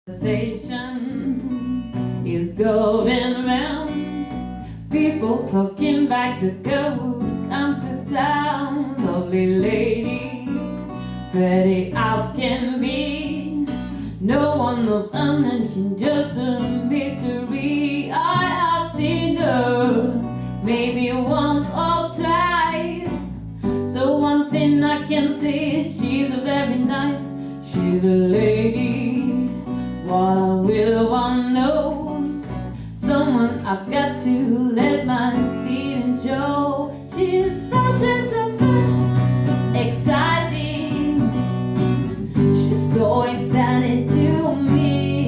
Disco / Funk